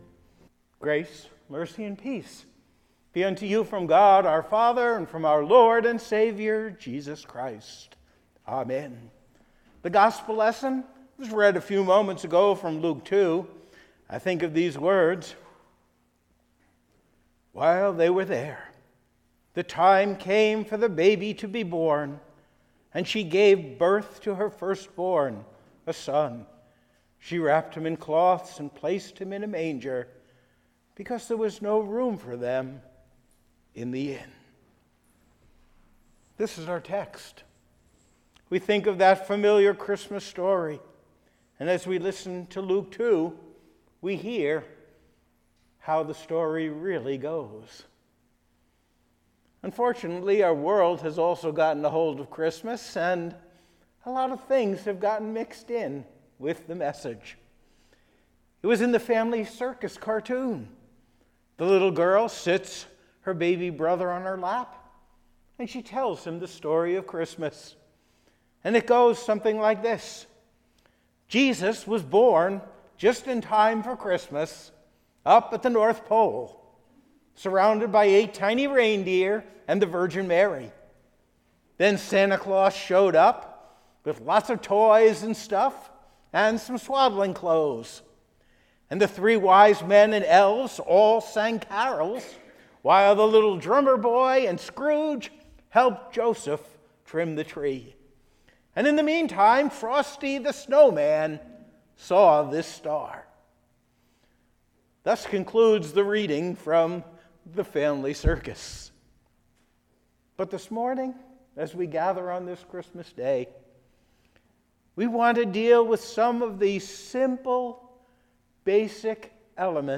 December 25th Christmas Day Sermon and Service
Listen to the recorded sermon and service from Zion Lutheran Church.
Sermon_Dec25Christmas.mp3